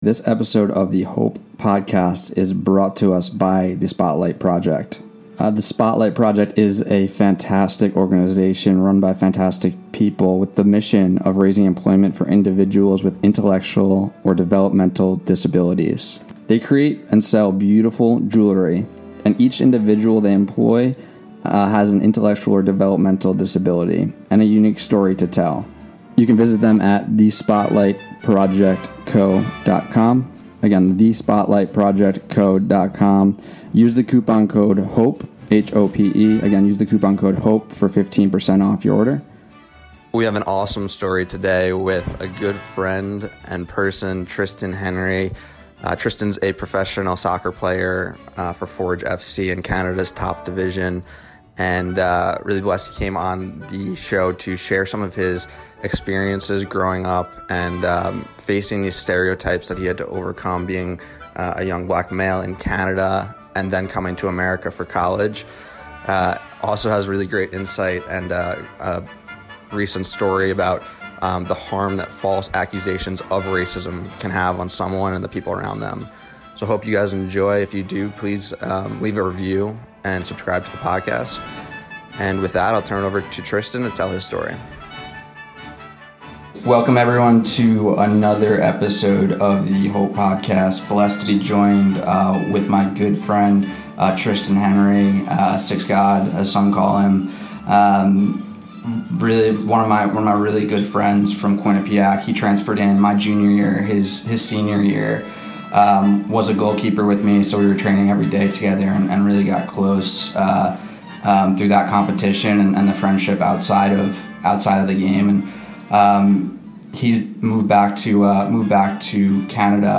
Inspiring conversations about overcoming adversity, motivation and the issues our generation faces.